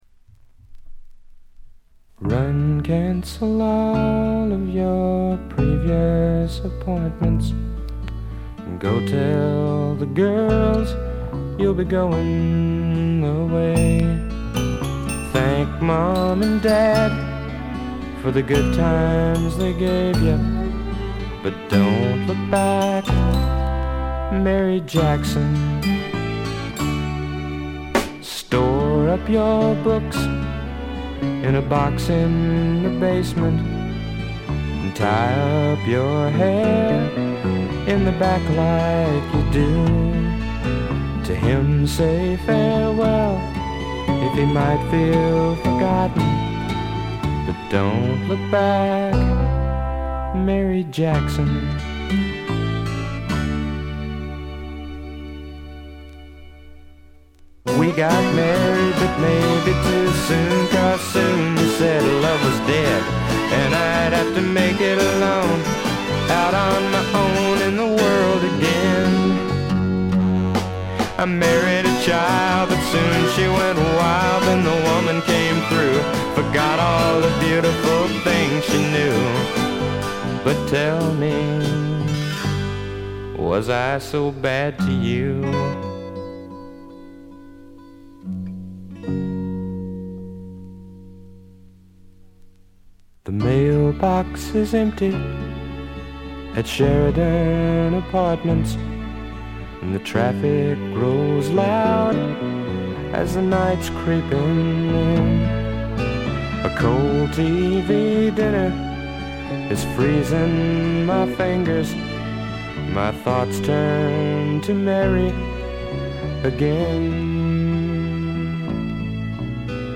部分試聴ですが、ほとんどノイズ感無し。
試聴曲は現品からの取り込み音源です。